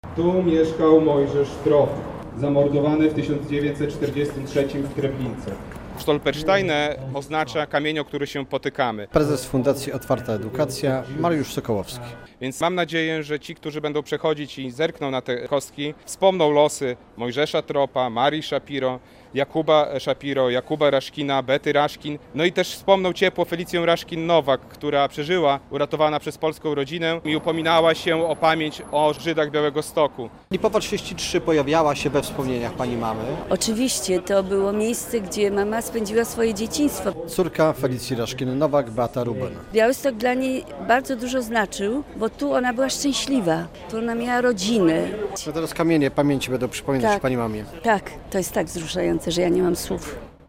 Kamienie pamięci na ulicy Lipowej - relacja